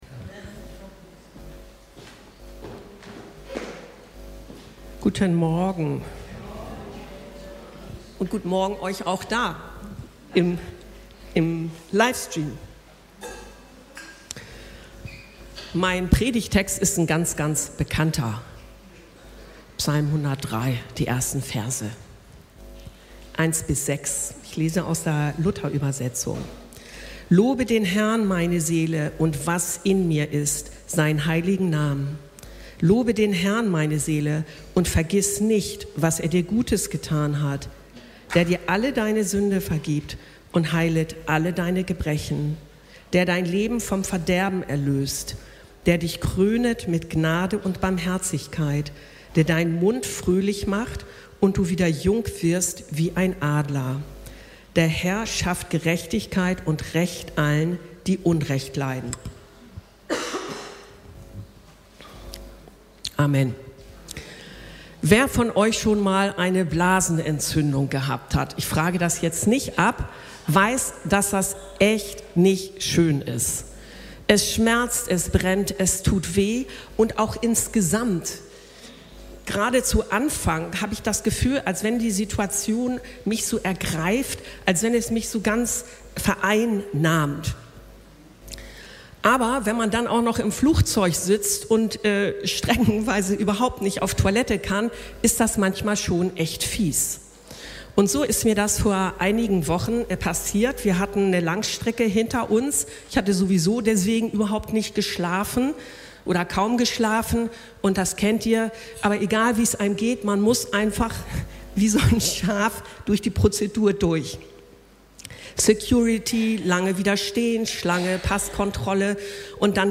Lobe den Herrn meine Seele ~ Anskar-Kirche Hamburg- Predigten Podcast